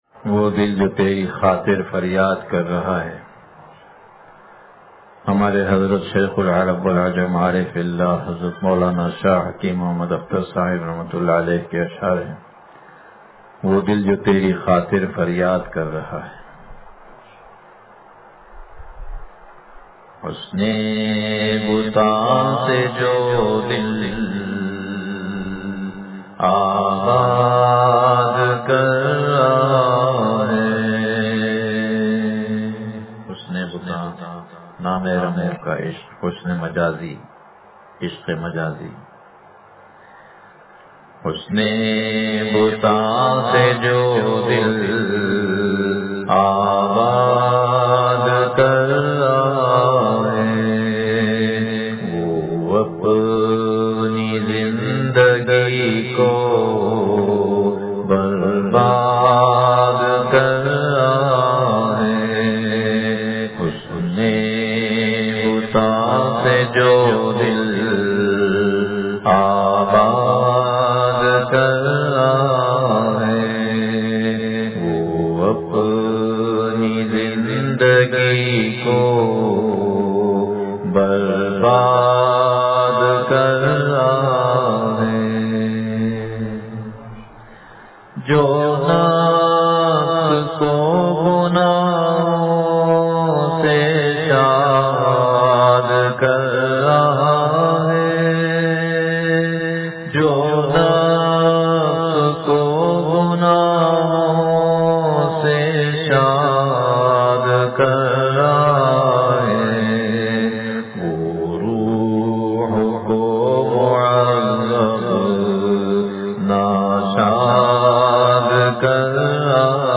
وہ دل جو تیری خاطر فریاد کر رہا ہے – مجلس بروز بدھ